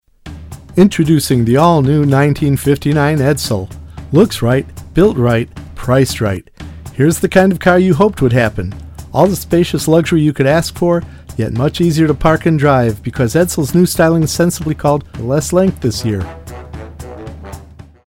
• Commercials
With a tone that’s clear, friendly and easy to listen to, I help brands share their stories, reach their audiences, and create personal experiences.
1959_Edsil_with-Music.mp3